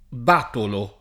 b#tolo] (ant. batalo [b#talo]) s. m. — es.: vestito di scarlatto e con un gran batalo [